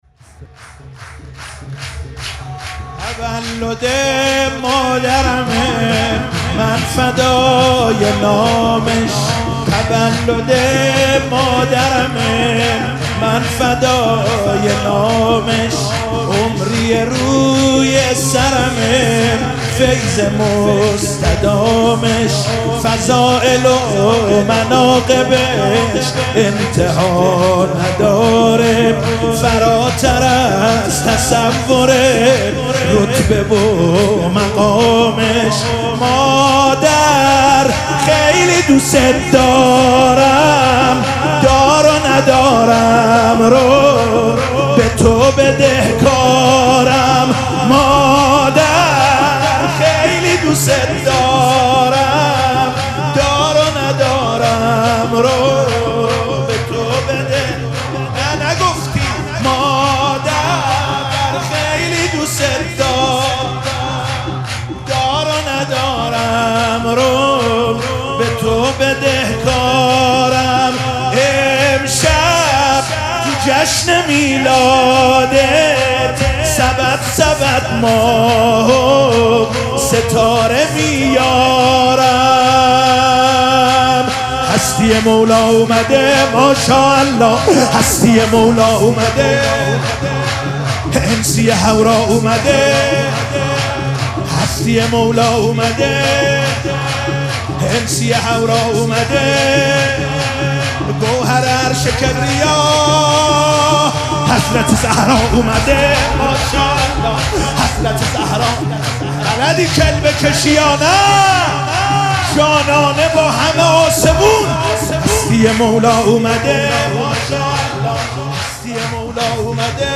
مراسم جشن ولادت حضرت زهرا سلام الله علیها
سرود